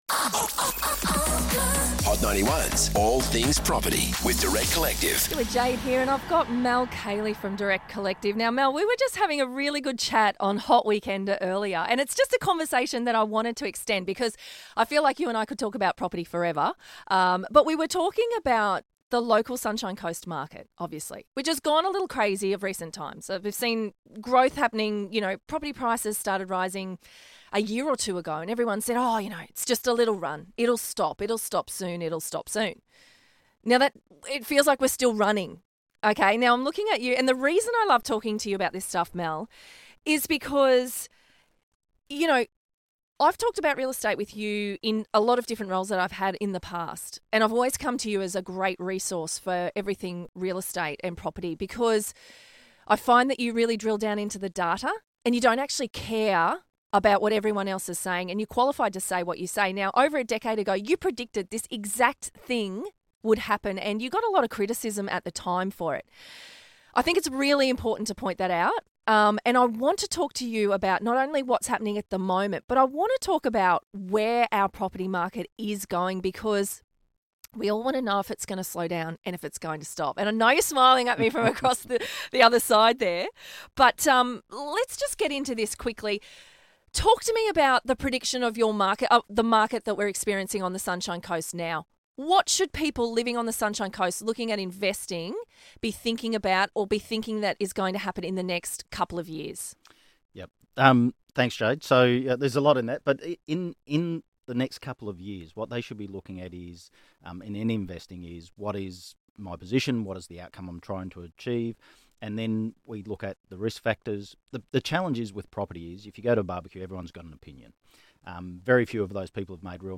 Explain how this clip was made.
in this extended on-air chat